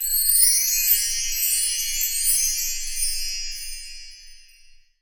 Звуки волшебства
• Качество: высокое